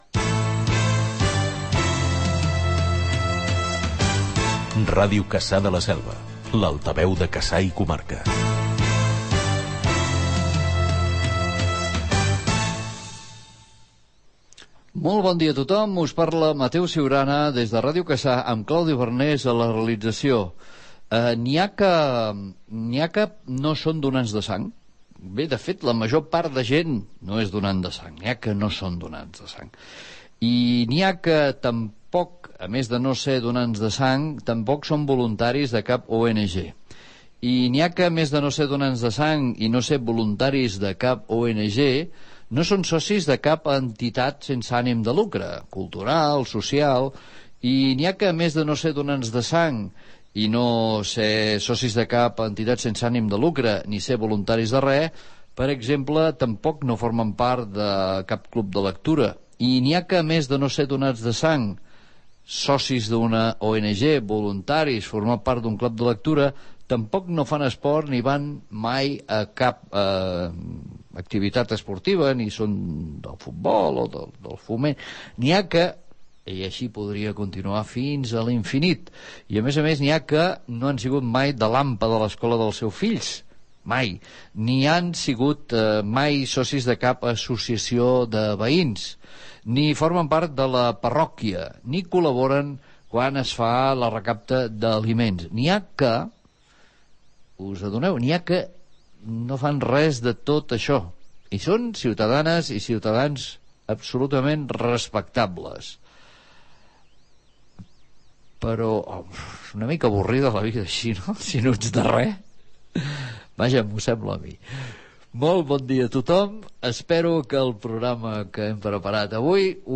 Indicatiu de l'emissora, inici del programa amb una editorial sobre les persones que mai han estat voluntàries, indicatiu del programa, sumari dels continguts i presentació del llibre "El voluntari".
Informatiu
FM